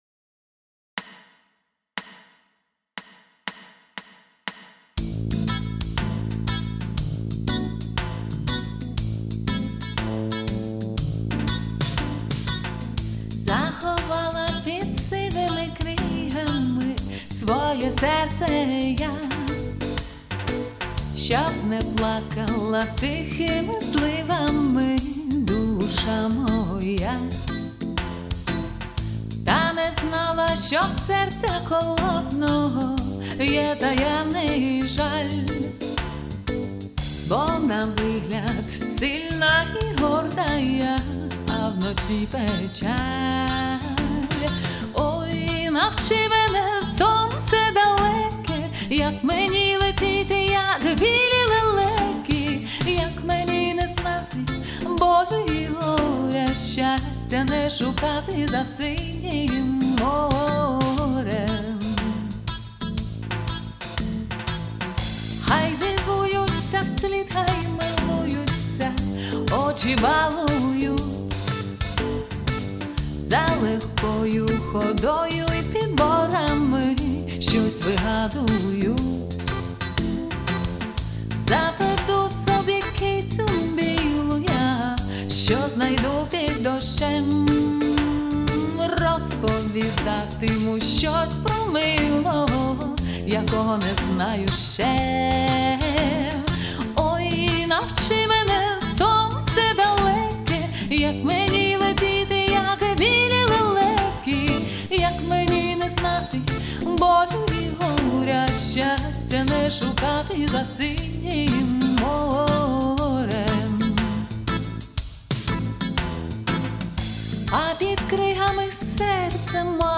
Рубрика: Поезія, Авторська пісня
Чудове Ваше реггі give_rose